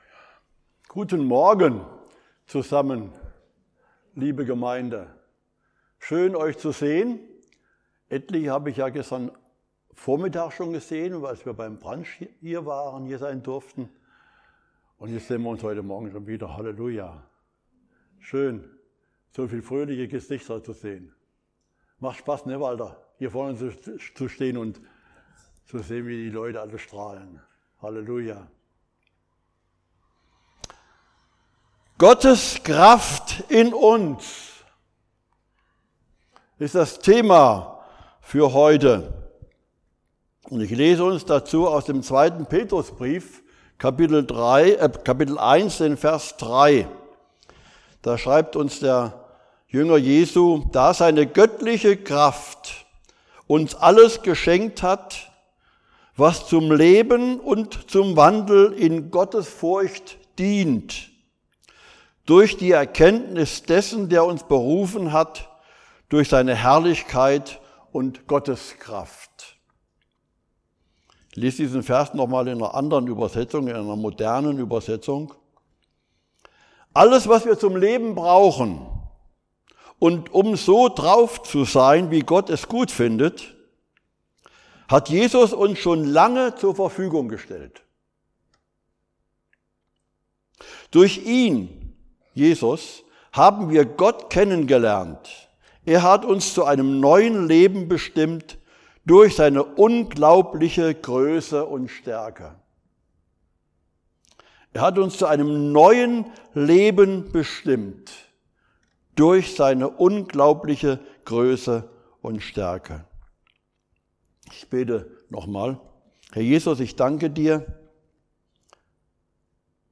Mose 1:27+28 Dienstart: Predigt Viele Menschen erleben Zeiten, in denen sie sich hilflos, machtlos oder ausgeliefert fühlen – der Welt, den Umständen, oder sogar sich selbst.